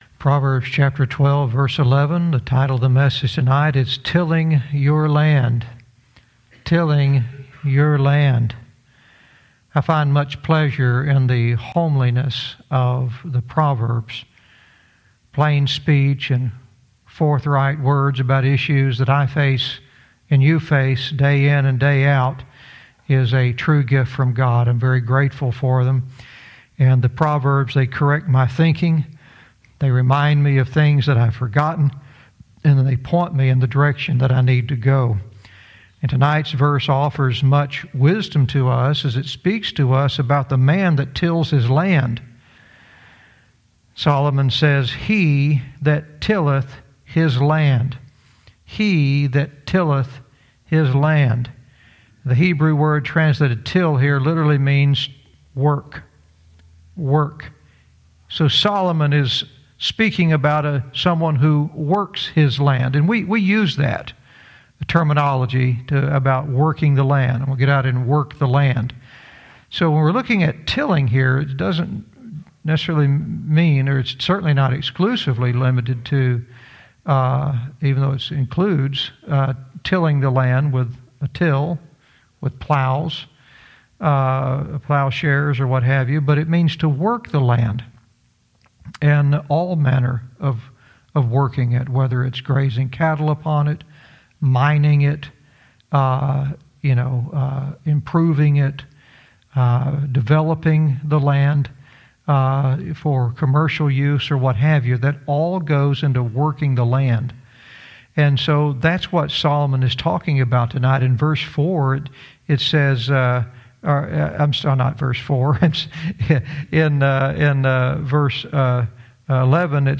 Verse by verse teaching - Proverbs 12:11 "Tilling Your Land"